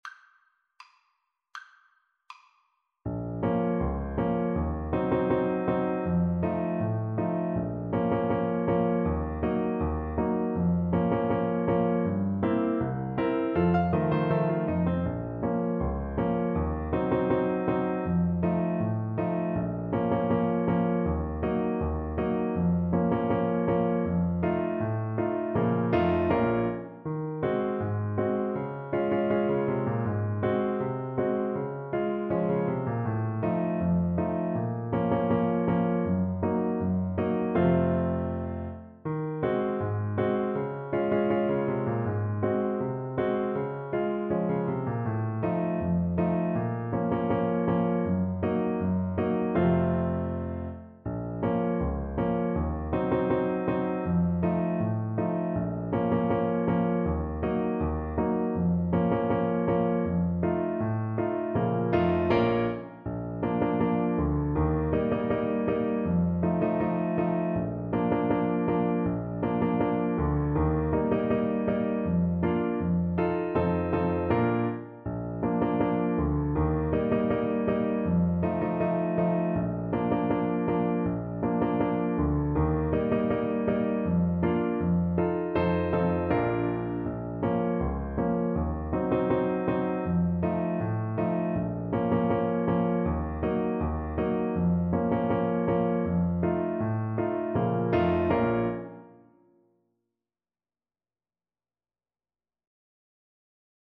Alto Saxophone version
Alto Saxophone
=c.80 Andante
2/4 (View more 2/4 Music)
Classical (View more Classical Saxophone Music)